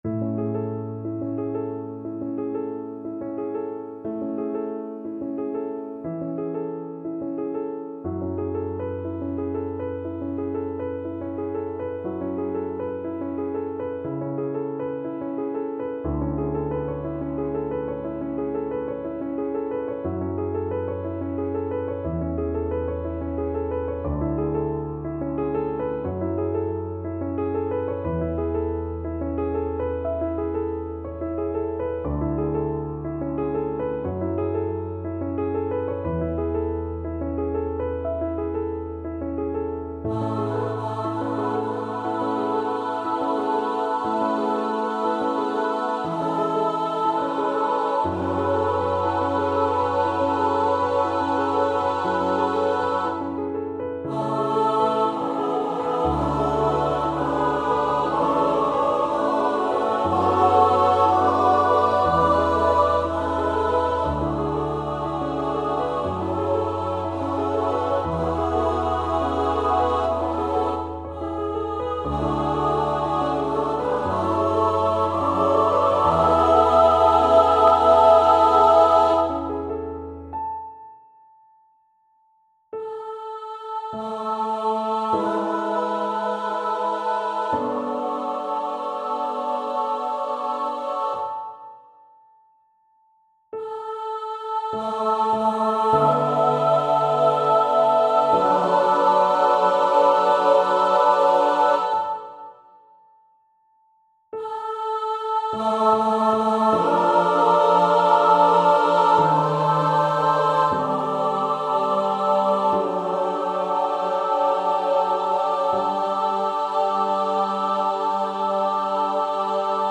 SATB Chorus & Piano